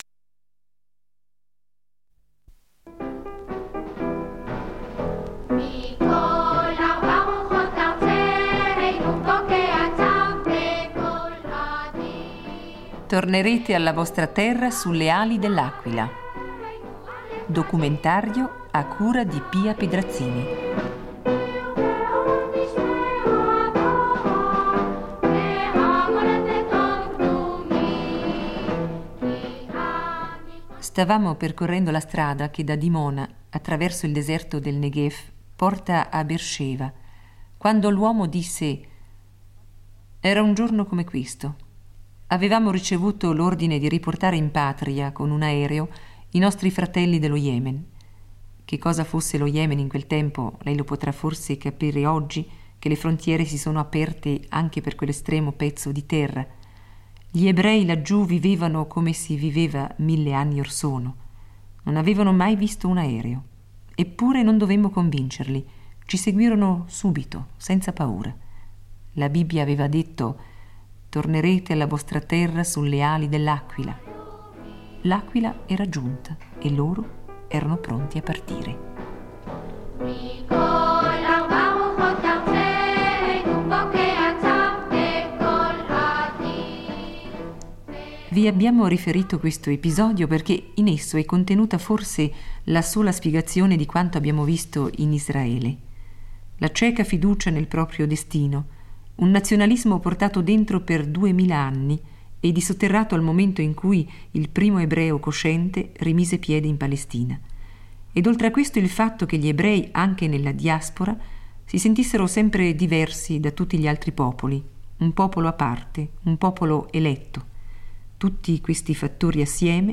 Il reportage cerca affronta inoltre il tema della convivenza con la popolazione araba.